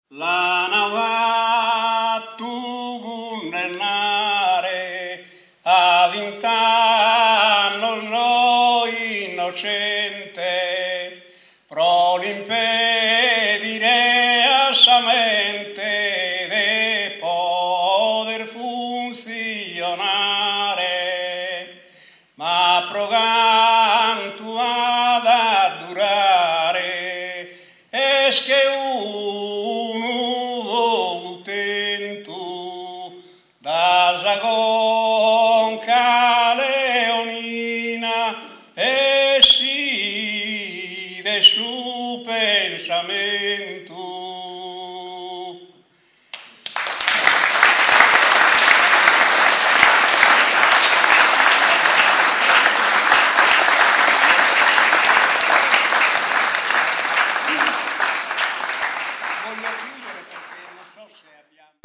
Il Cantu pro Gramsci al III convegno della IGS